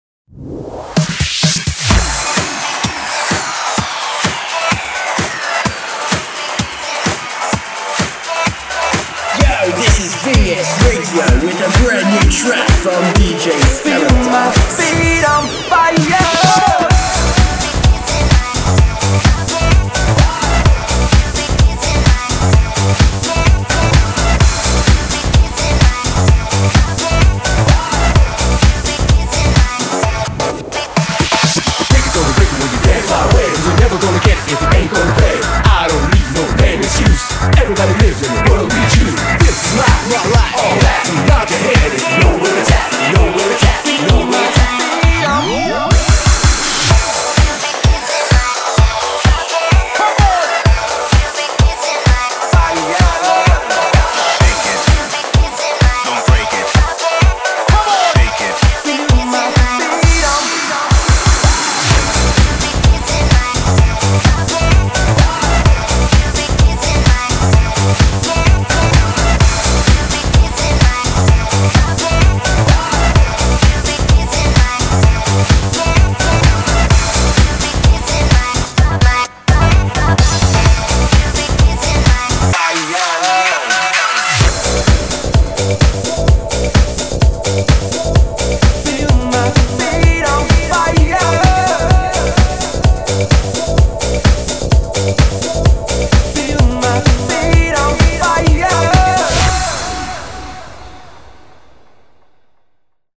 BPM128
Audio QualityPerfect (High Quality)